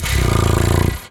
cat_2_purr_04.wav